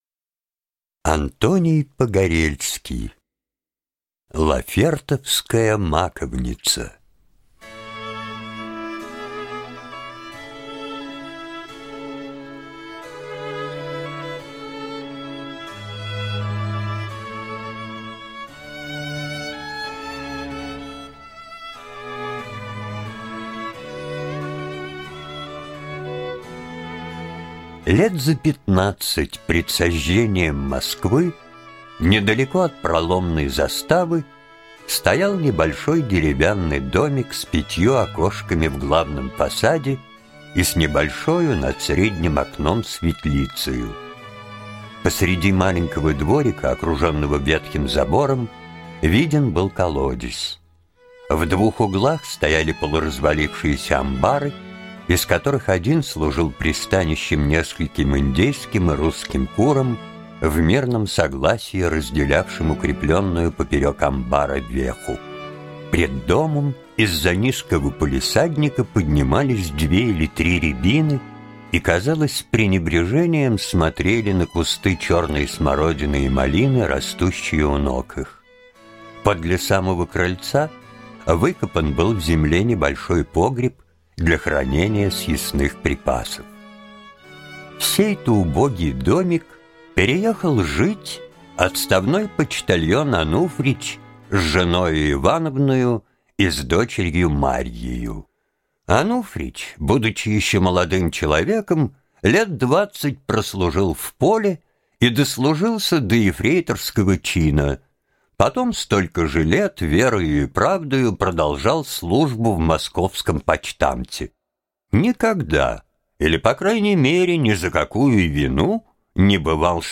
Лафертовская маковница - аудио рассказ Погорельского